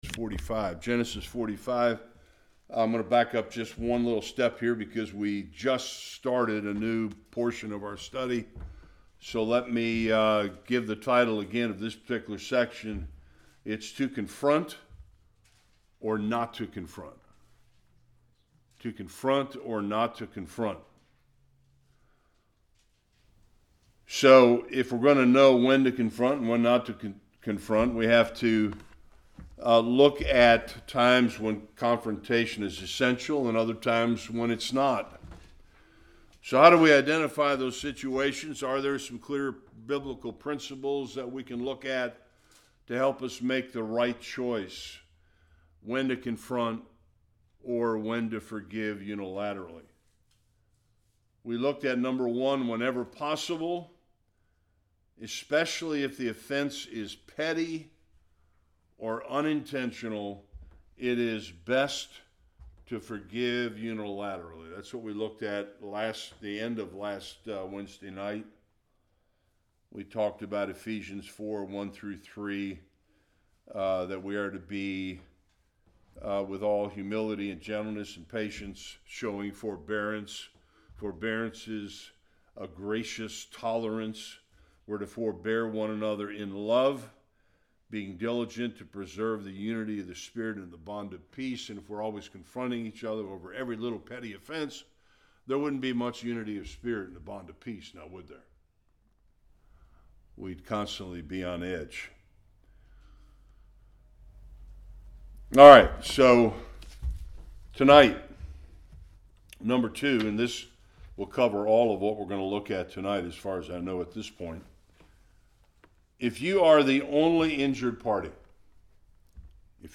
Various Passages Service Type: Bible Study The Bible has several examples of unconditional forgiveness.